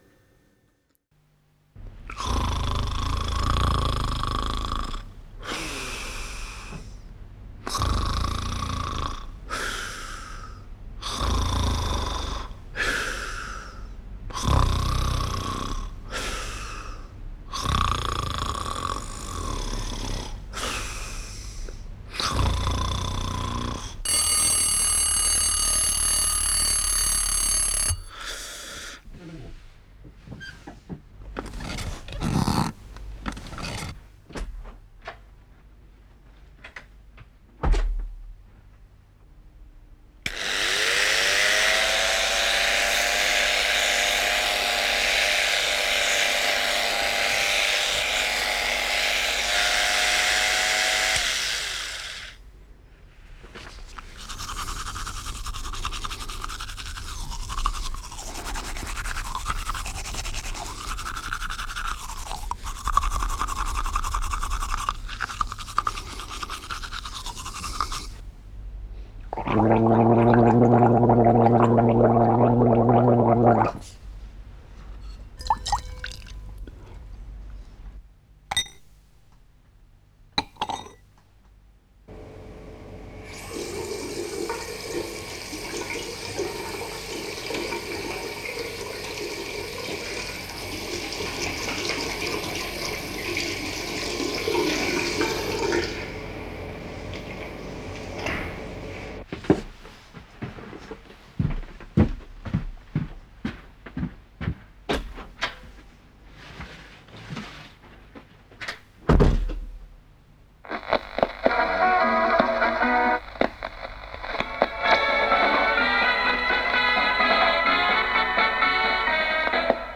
Geräusche-Zwillinge Download Station A4: Geräusche-Geschichte Diese Geräusche erzählen euch eine Geschichte. Erzählt und spielt sie nach.
37Geräusche-Geschichte.wav